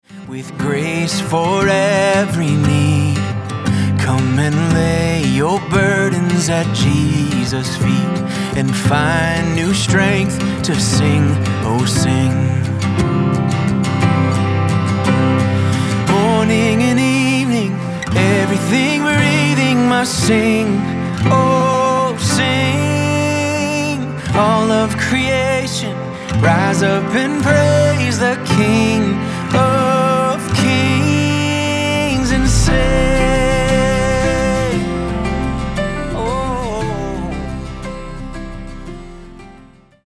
Guitar Chart - Recorded Key (C)